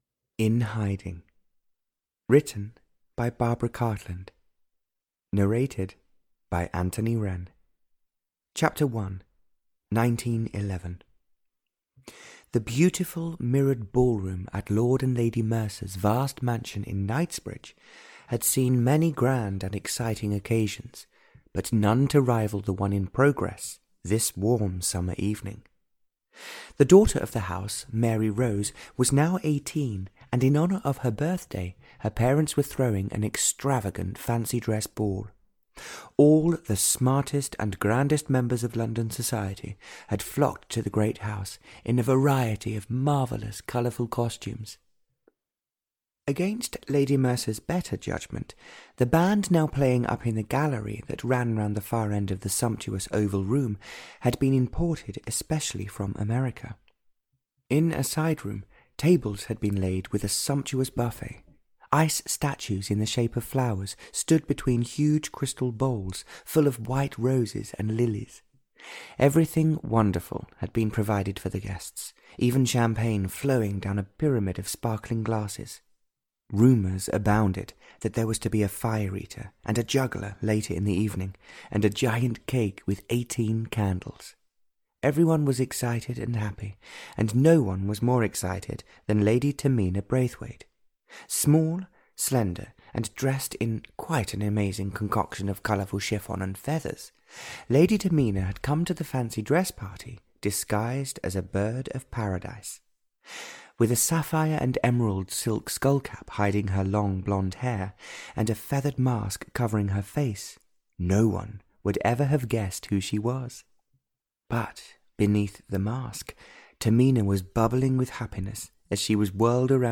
In Hiding (EN) audiokniha
Ukázka z knihy